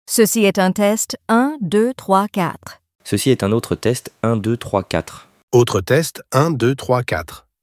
• Activation de l’intégration et ajout d’une voix par défaut,
• Ajout de deux TAGs supplémentaires (test1 et tests).